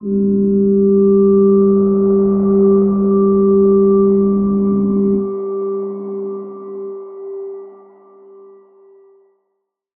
G_Crystal-G4-f.wav